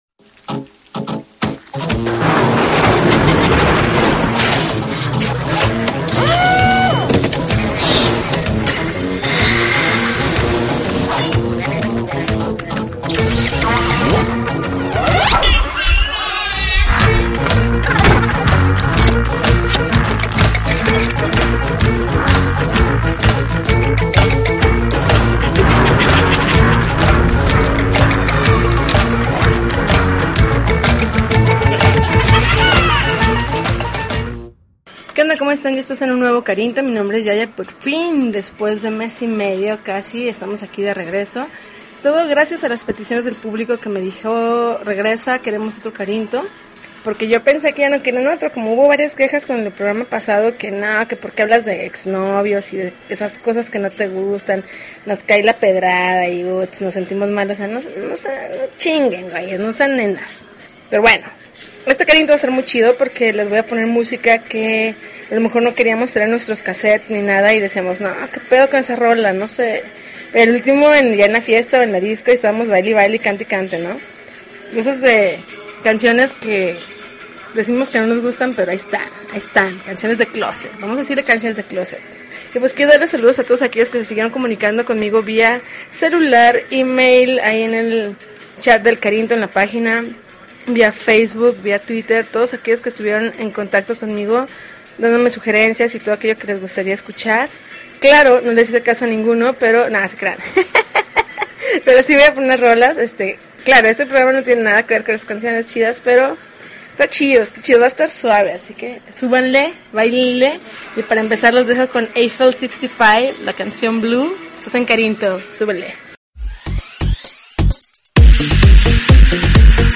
August 3, 2013Podcast, Punk Rock Alternativo